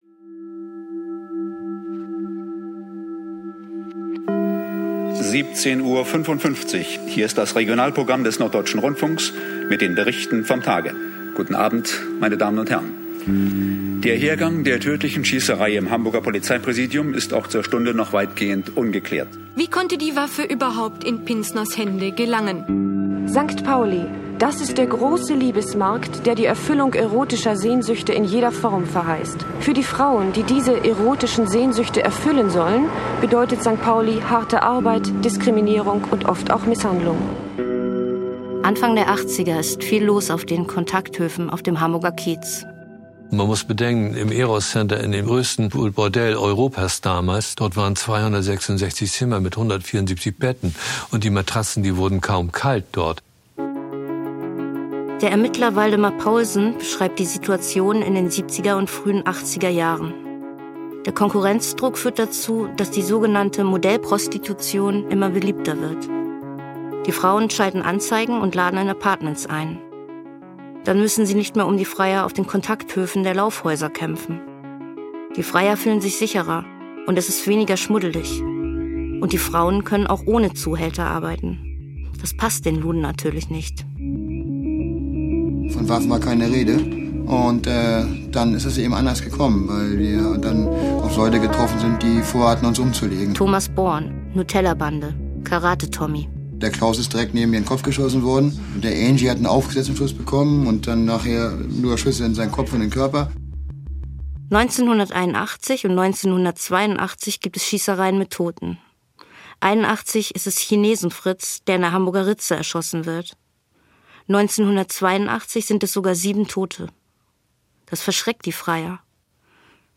Erzählerin im Podcast: Julia Hummer.